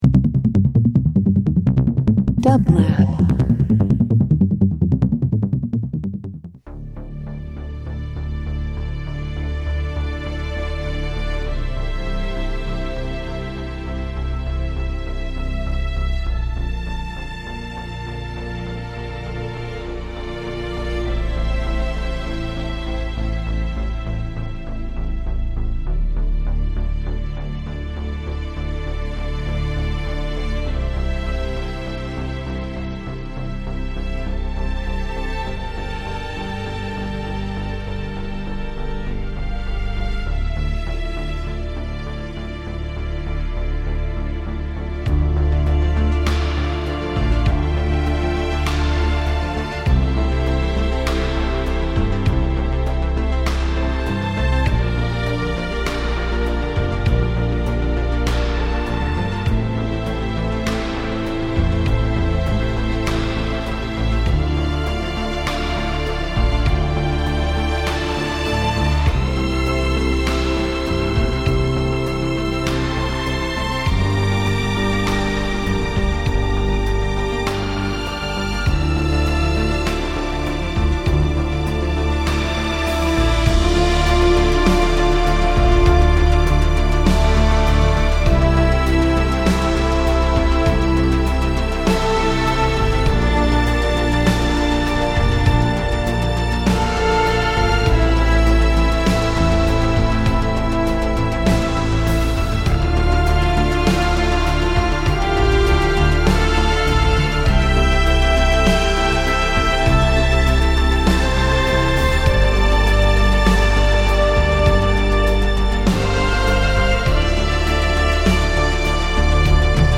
Studio Soundtracks takes listeners behind the scenes of how music is crafted for film and television by hearing directly from composers, songwriters and music professionals in the Entertainment Industry. Listen to inspiring conversations about composition and hear works from Emmy, Grammy, and Oscar-winning film scores on the show.